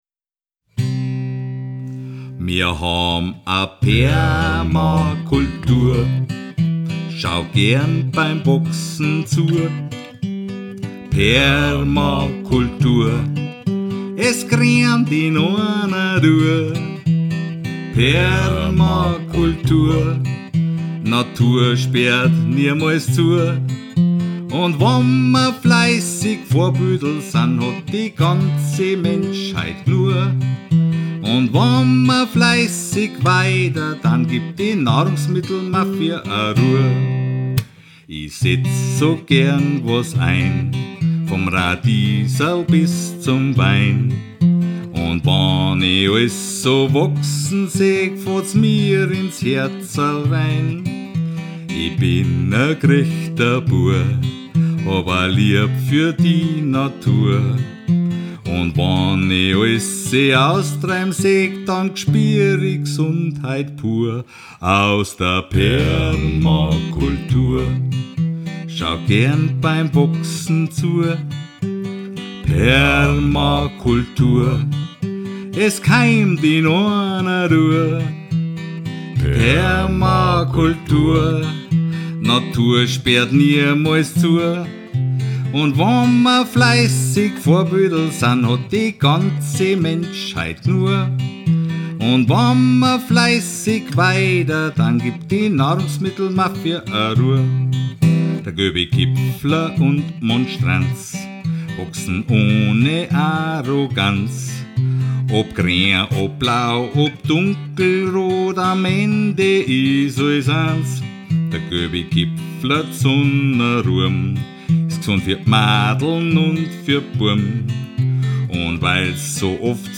Studioversion – rough an dirty